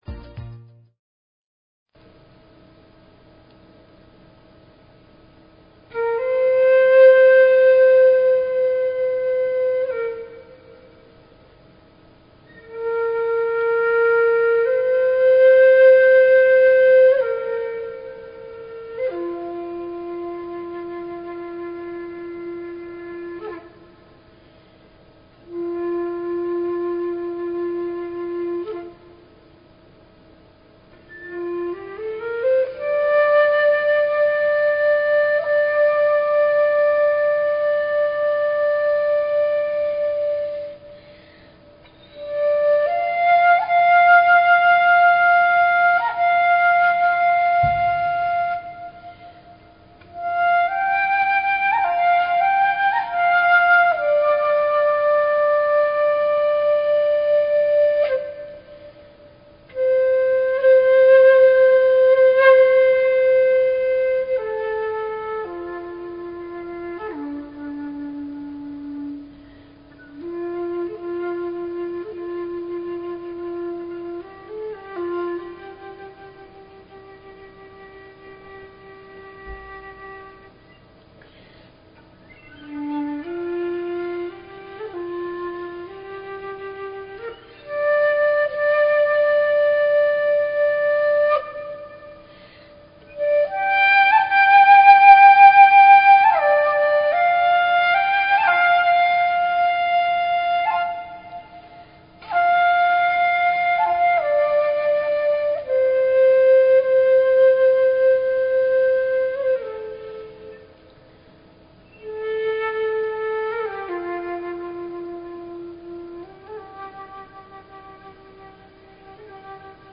Talk Show Episode, Audio Podcast
The Elohim share how nodes of energy/frequency created space and time where none existed previously. As they communicated this information they connected up with the energies of listeners and at the end of the show, people had the opportunity to make silent requests.